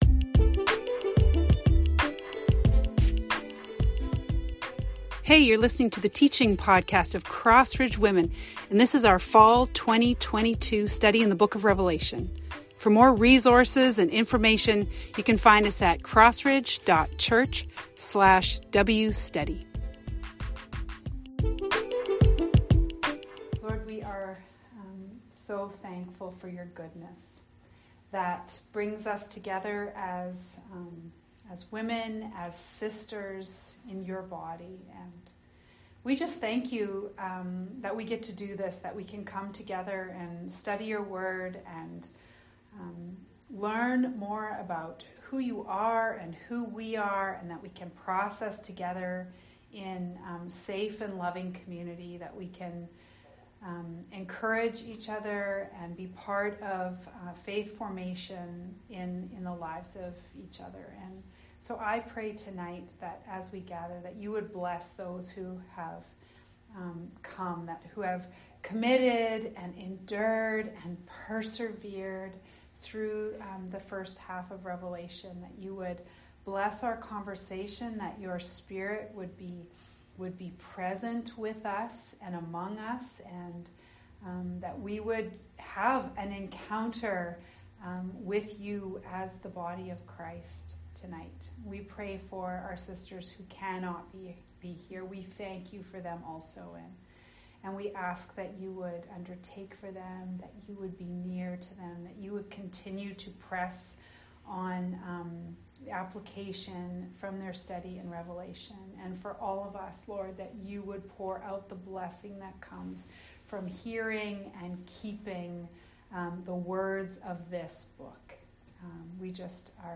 Large group discussion and observations from our study of Revelation 10-11.